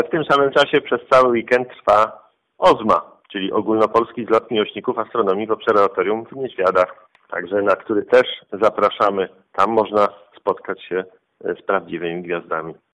Oprócz koncertów  w nadchodzący weekend znajdzie się również coś dla miłośników astronomii. W pobliskich Niedźwiadach odbywa się bowiem XIX Ogólnopolski zlot OZMA 2015, do którego odwiedzenia również zachęca Burmistrz Artur Michalak